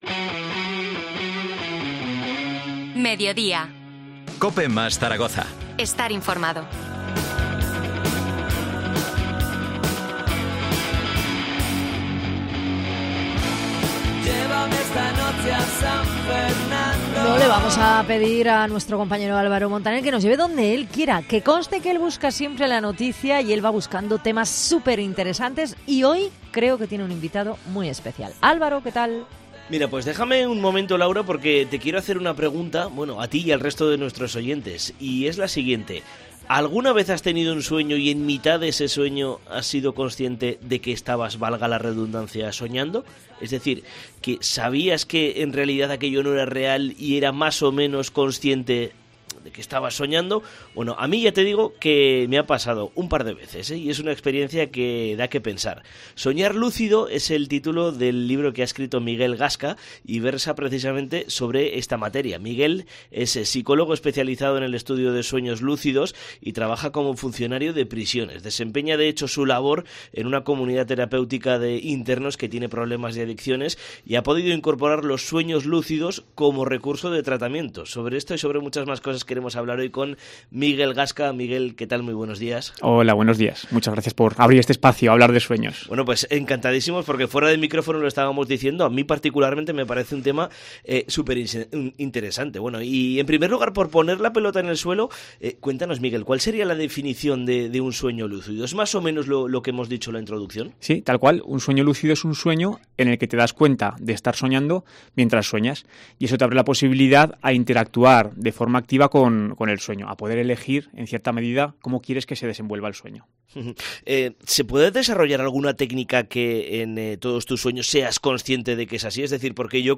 Zaragoza
Entrevista